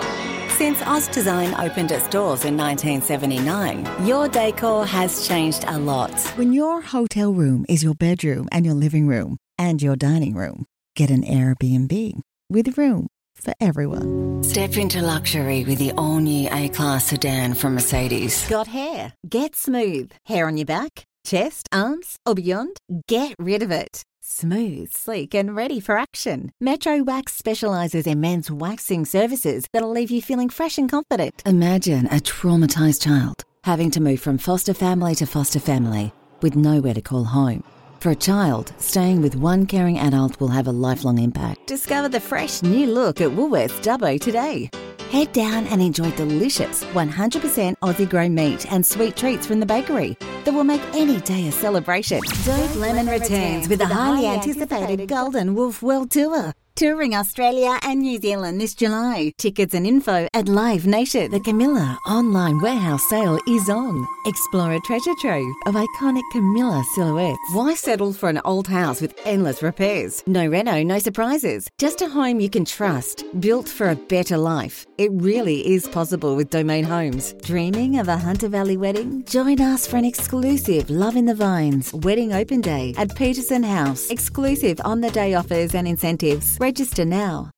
Inglés (Australia)
Carismático
Atractivo
Versátil